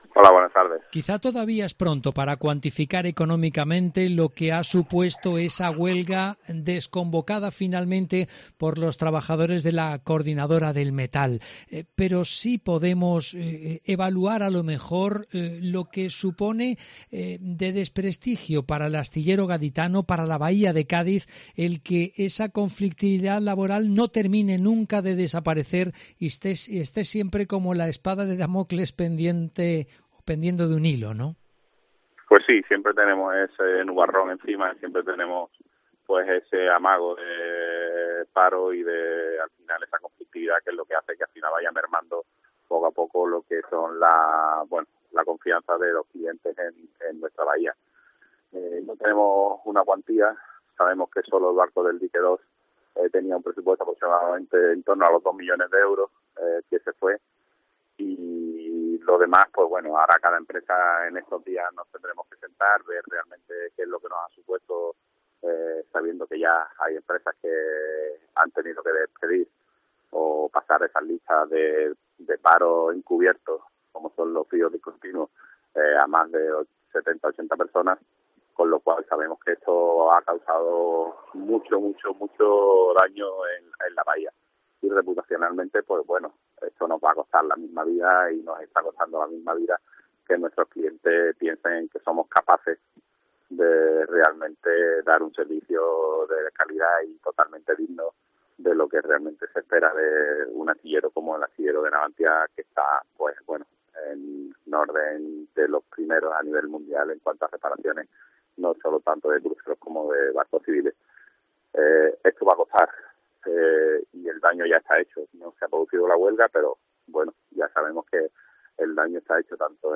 Hablamos de la conflictividad laboral en el astillero de la capital gaditana